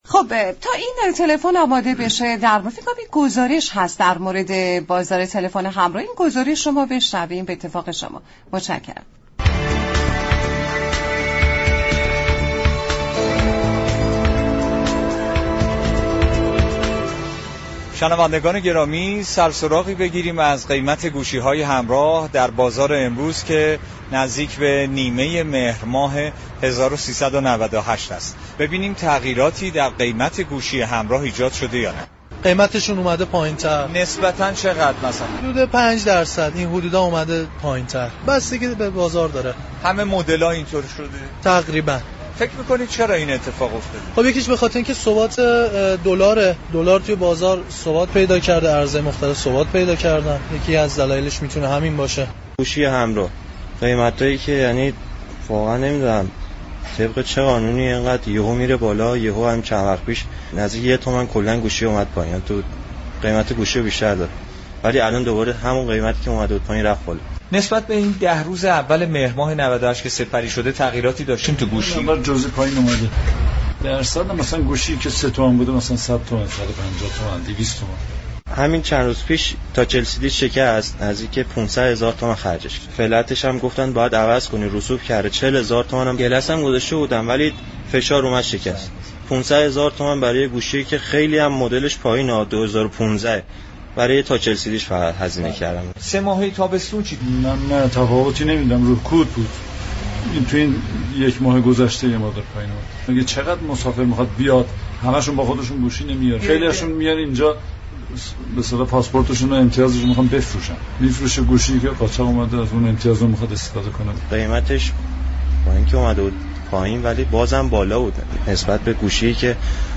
گفت و گو با برنامه «نمودار»